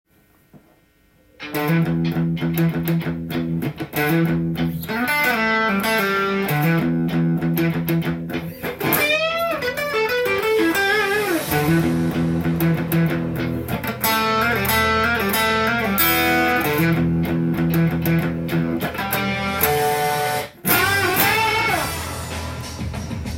イントロ改造TAB譜
音源に合わせて譜面通り弾いてみました
イントロは、Eマイナーペンタトニックスケールで作ったリフの繰り返しです。
そこでギターソロを弾けるようにフレーズをはめ込んでみました。
もちろんスケールはEマイナーペンタトニックスケールです。
オクターブチョーキングで締めるという　改造TAB譜になっています。